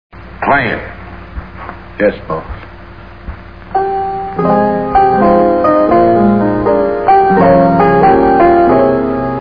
Music: a few bars of As Time Goes By